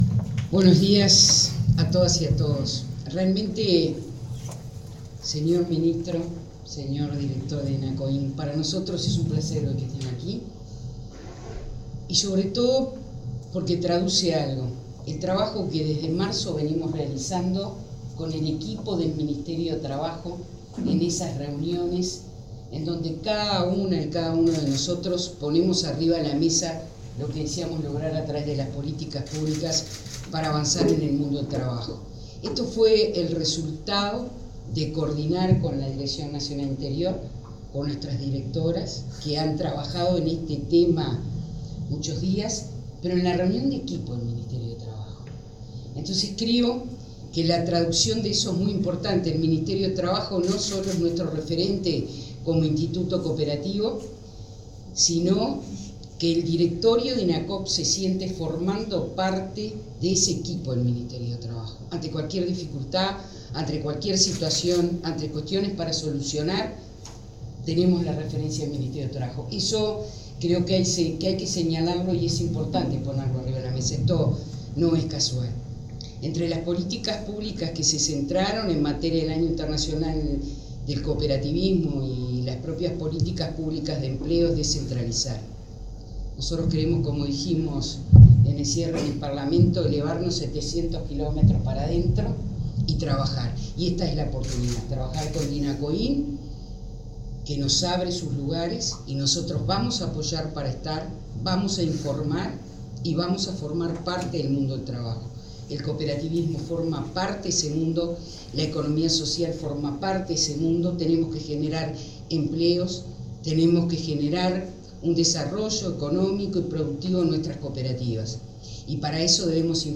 Palabras de la presidenta del Instituto Nacional del Cooperativismo, Graciela Fernández